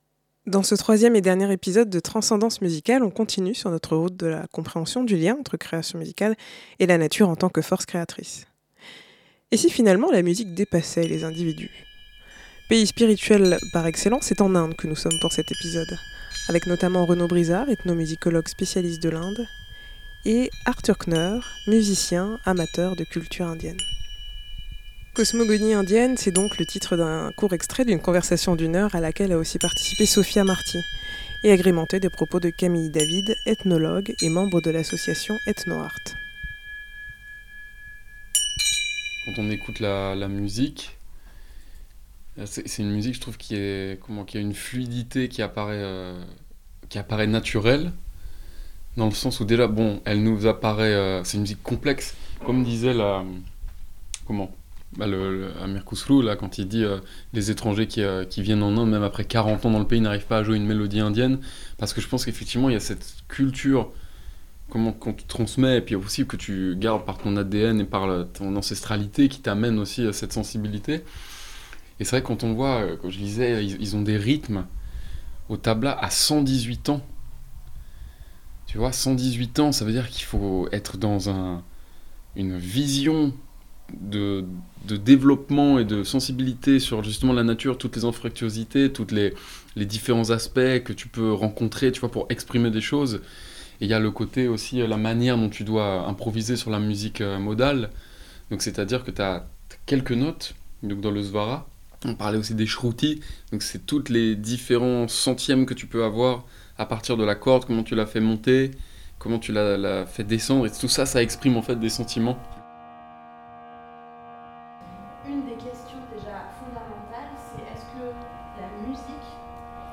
Type Création sonore